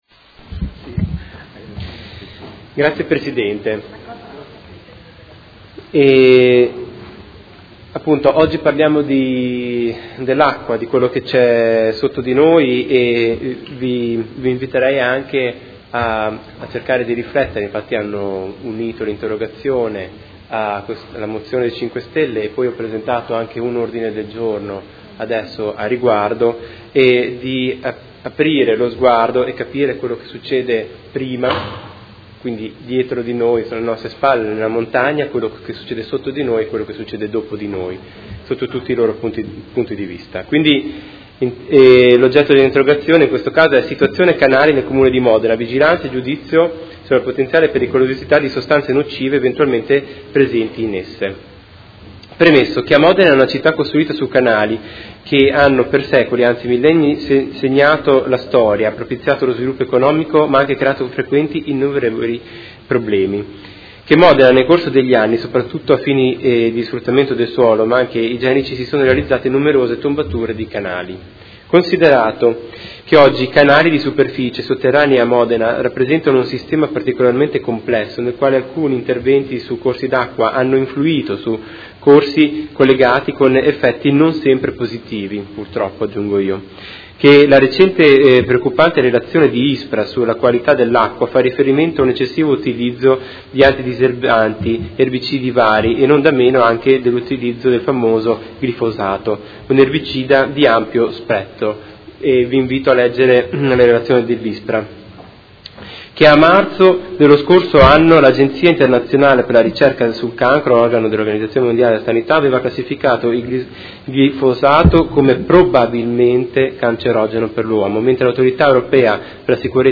Marco Chincarini — Sito Audio Consiglio Comunale